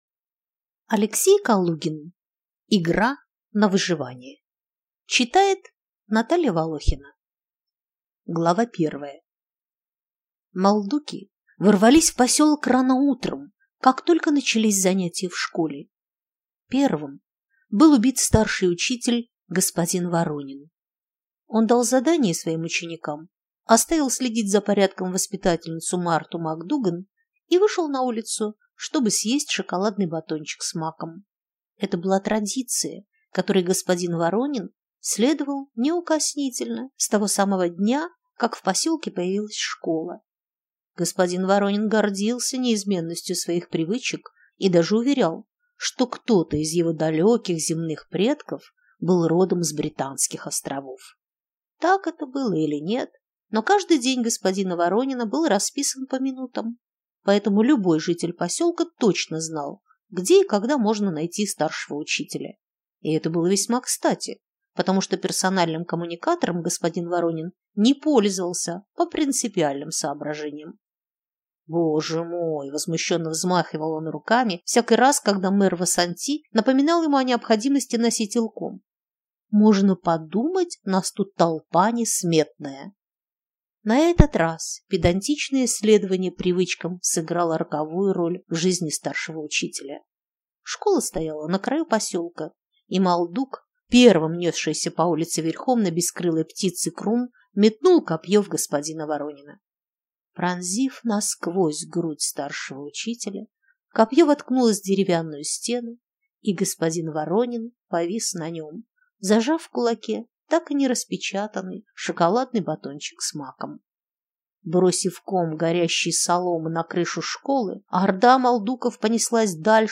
Аудиокнига Игра на выживание | Библиотека аудиокниг